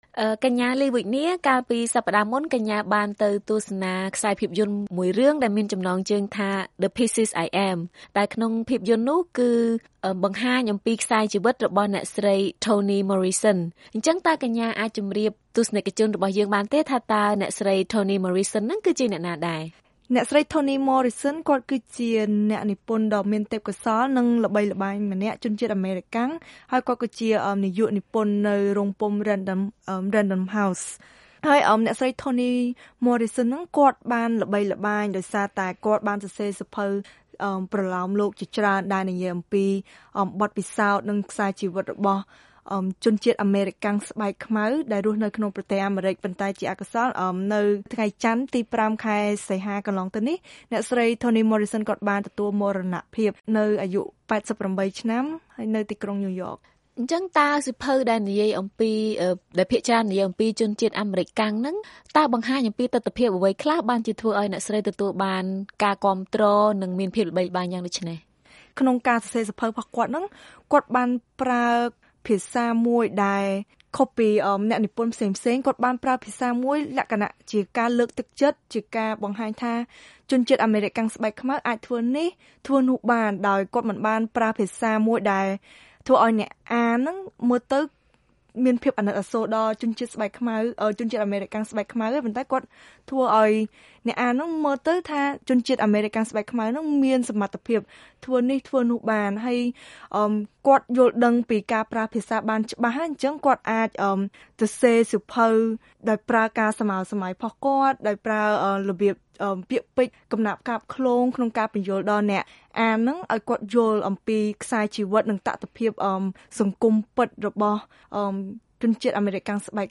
កិច្ចសន្ទនា VOA៖ កេរដំណែលអ្នកស្រី Toni Morrison បានជួយដល់សហគមន៍ពលរដ្ឋអាមេរិកាំងដើមកំណើតអាហ្វ្រិក